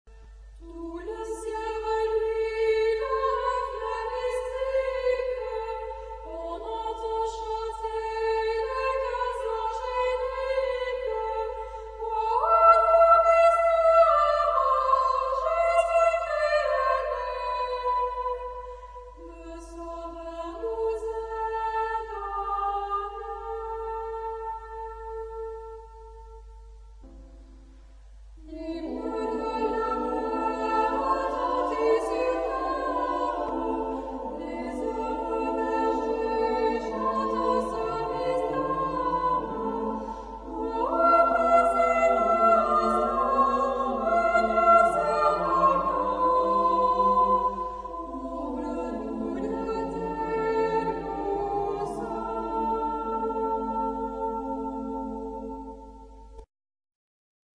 Género/Estilo/Forma: Canción de Navidad ; Popular
Tipo de formación coral: AT O SAH O SATB  (4 voces Coro mixto O Coro a una voz )
Tonalidad : la menor